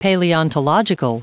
Prononciation du mot paleontological en anglais (fichier audio)
Prononciation du mot : paleontological